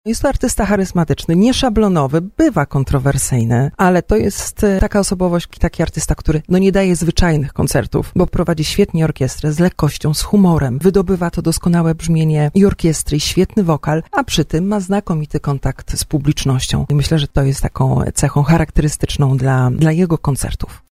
mówiła na antenie RDN Małopolska